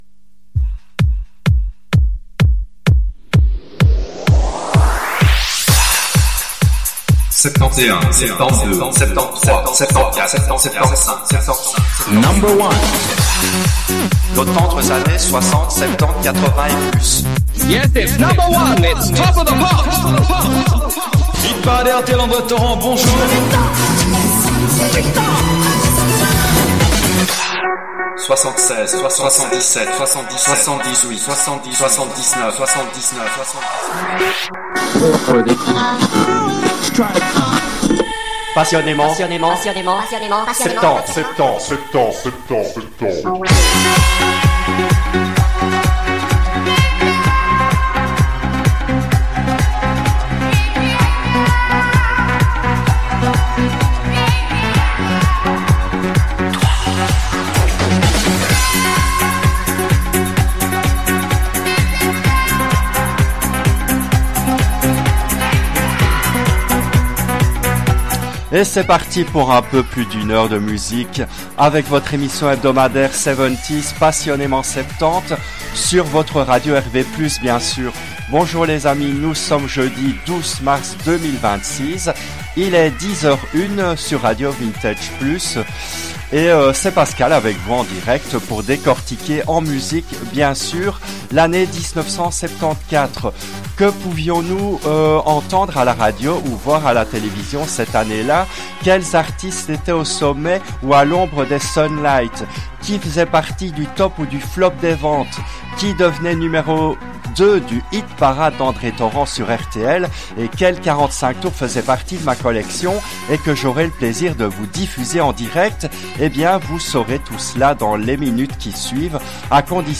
Cette émission a été diffusée en direct le jeudi 04 avril 2024 à 10h depuis les studios belges de RADIO RV+.